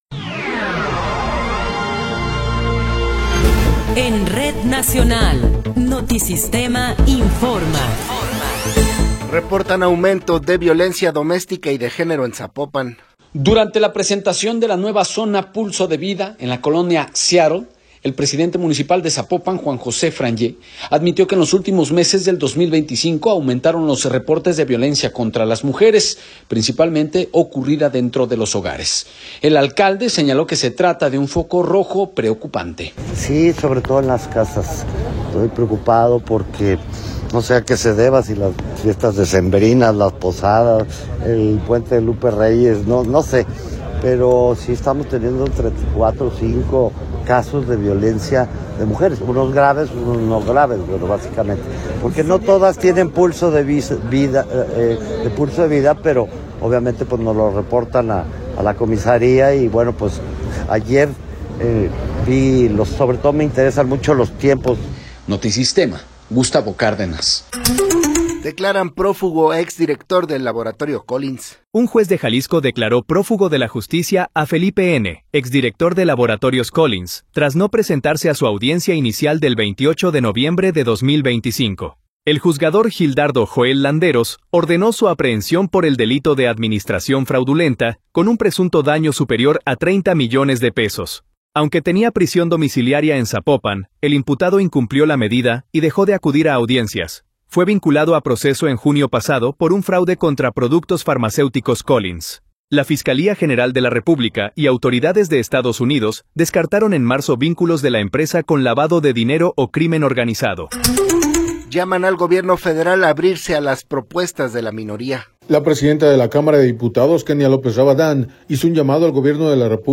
Noticiero 18 hrs. – 12 de Enero de 2026